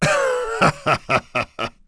Kaulah-Vox_Happy1.wav